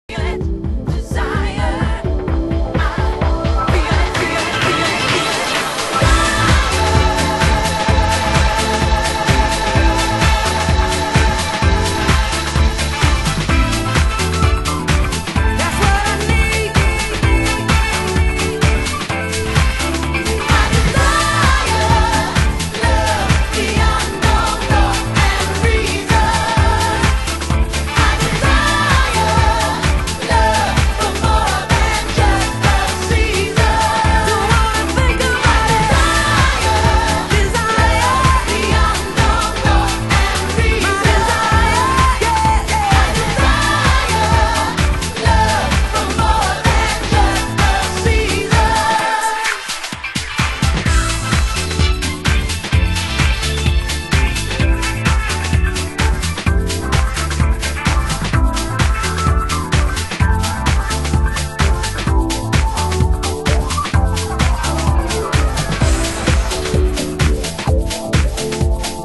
HOUSE MUSIC USED ANALOG ONLINE SHOP